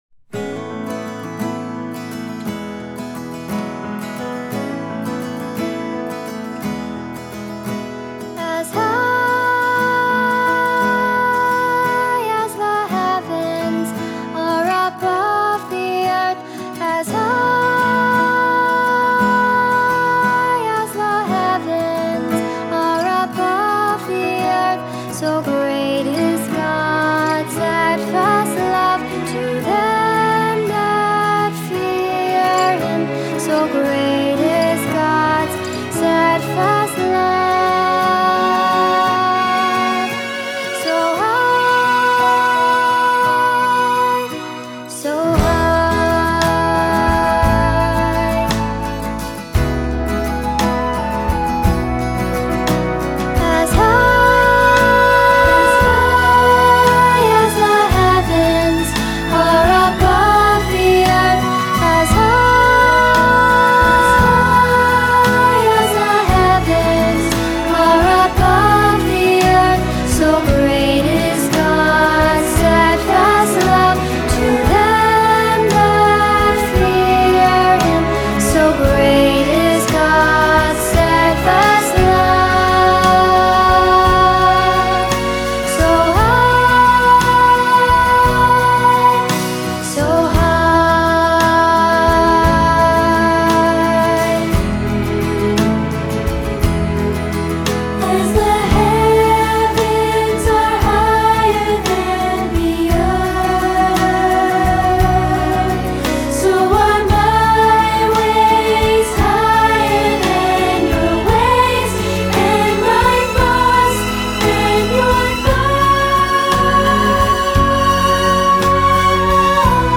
Unison/Two-part with piano